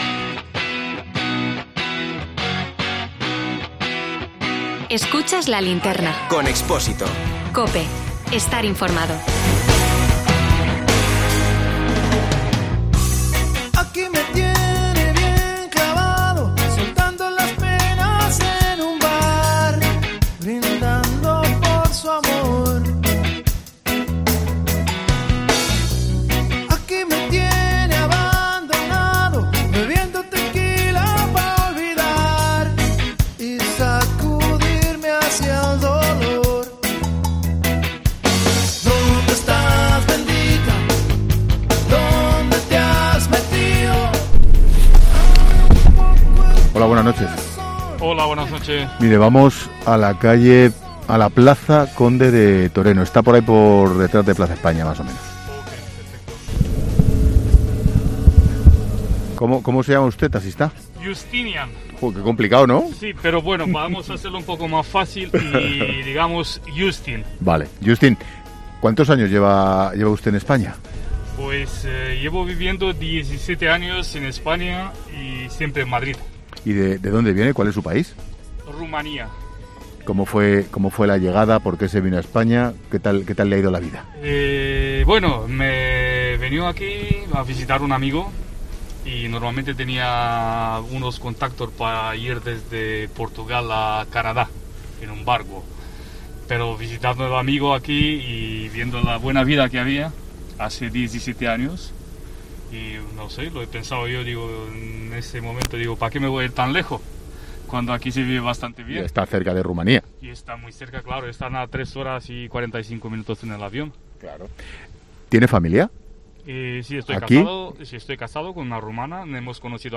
Esta noche lo que te propongo es salir a la calle, a la noche de Madrid para comprobar cómo lo está pasando el ocio nocturno. Así que acompáñame, vamos a salir del estudio. Vamos a coger un taxi y vamos a hablar con todos los actores implicados.